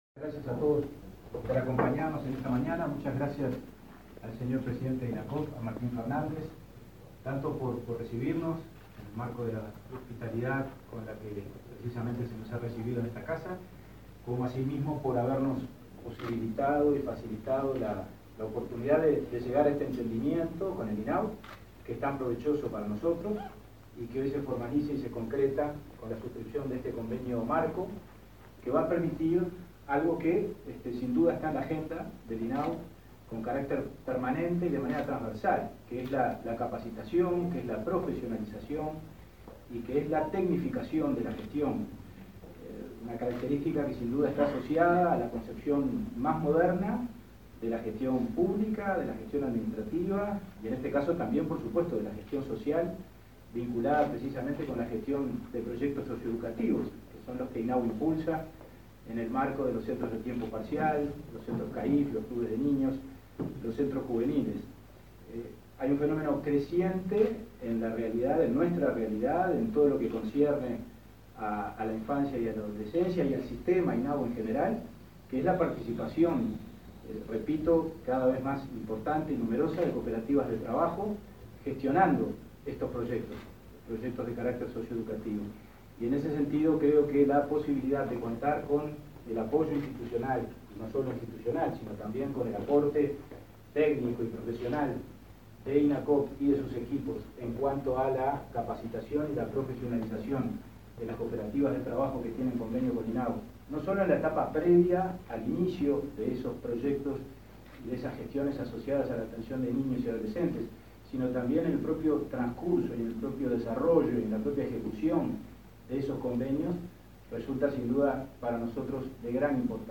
Palabras de autoridades en acto de firma de convenio entre INAU e Inacoop